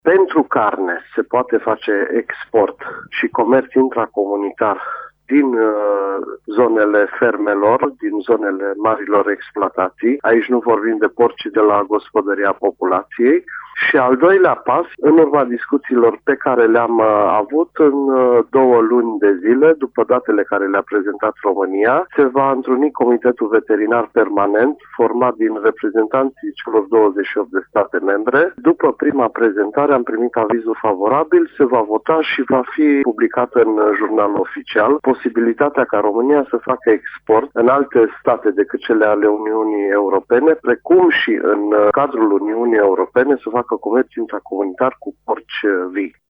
Acesta precizează speră ca peste 2 luni România să poată face comerț intracomunitar și cu porci vii: